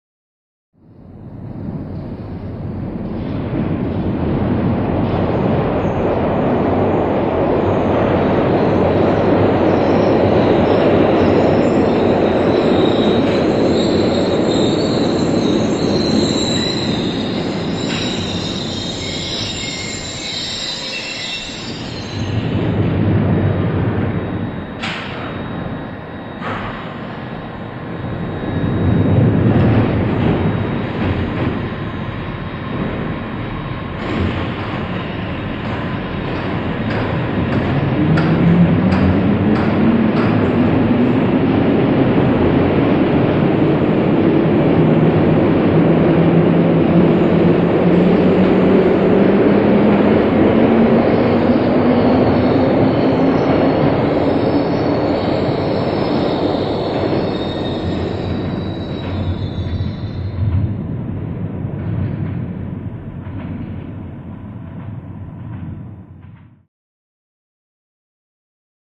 Звук движения электропоезда внутри вагона